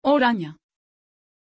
Pronunciation of Orania
pronunciation-orania-tr.mp3